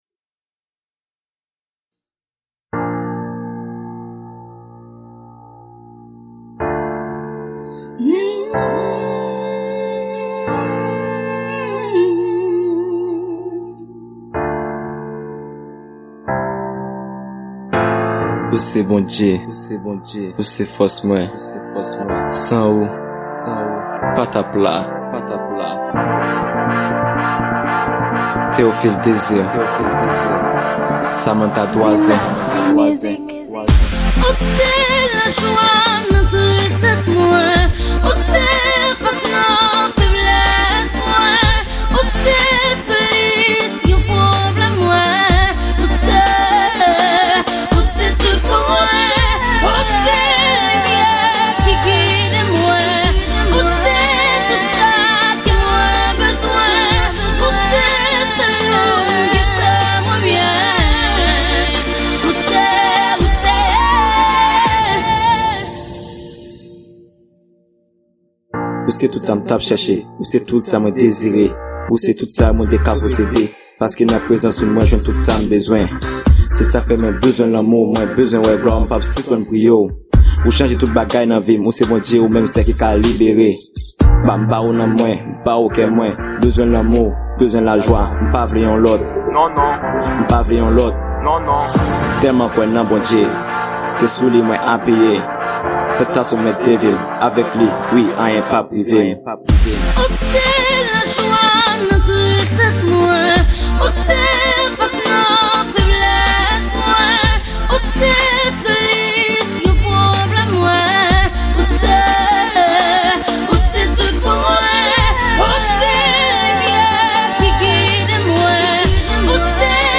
Genre: Gospel